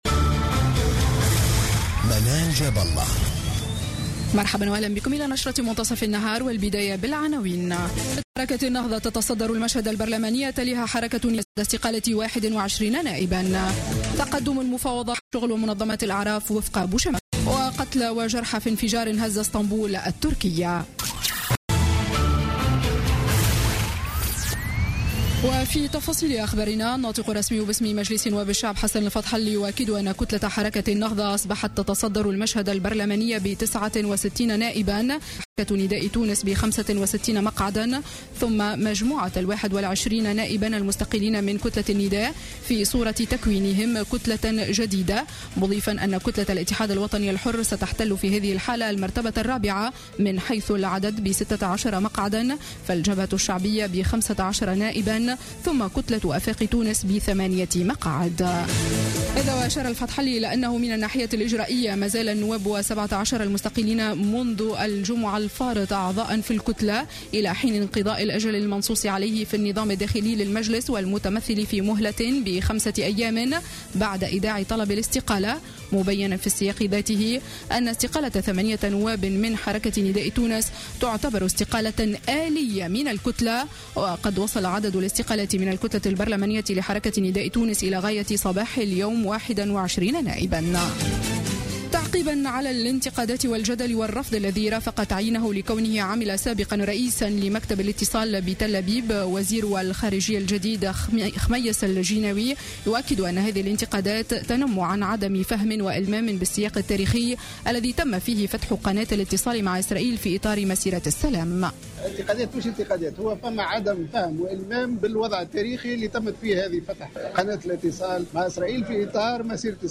نشرة أخبار منتصف النهار ليوم الثلاثاء 12 جانفي 2016